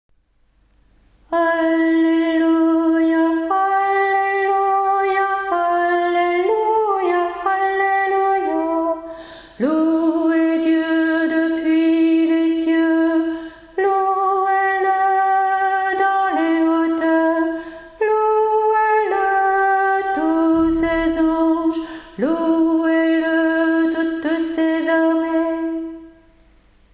Voix chantée (MP3)COUPLET/REFRAIN
ALTO